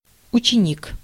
Ääntäminen
Translitterointi: utšenik.